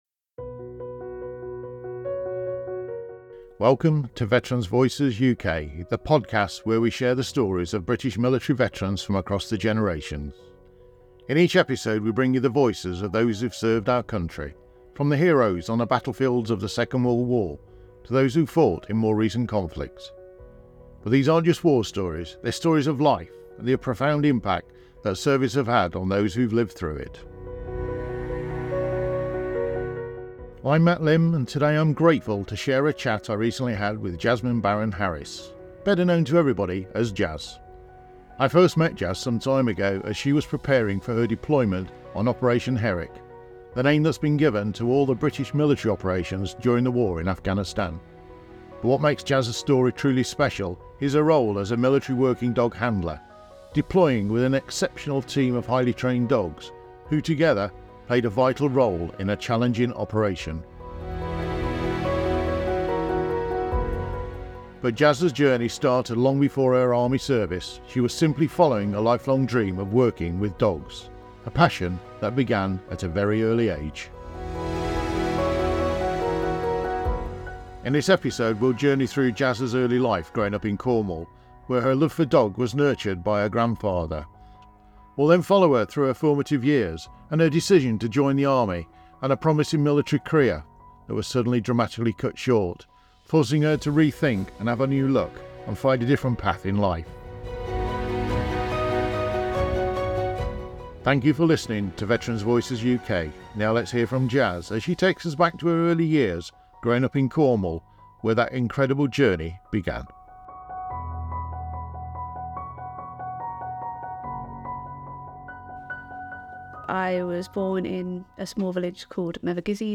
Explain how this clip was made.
Veterans Voices UK is a podcast dedicated to preserving the personal stories of British military veterans from across the generations. Through candid and heartfelt conversations, we explore not only their experiences of war and conflict but also the incredible people behind those stories.